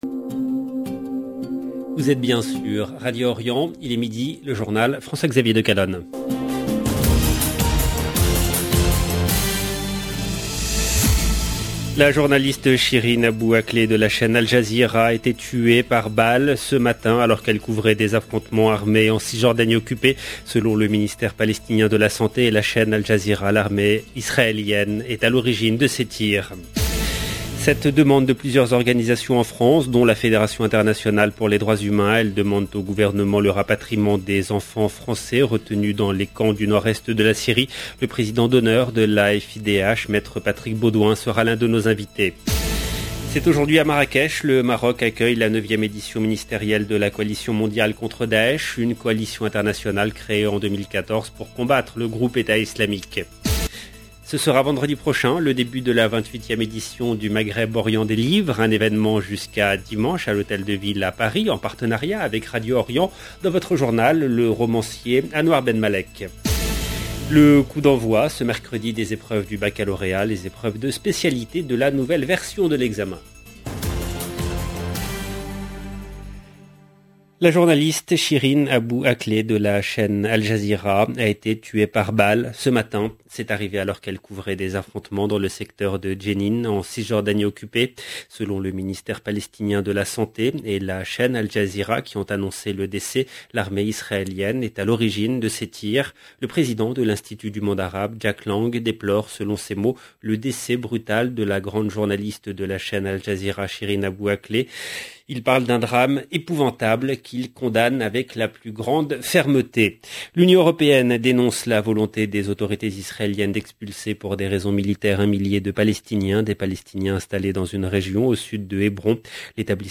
EDITION DU JOURNAL DE 12 H EN LANGUE FRANCAISE DU 11/5/2022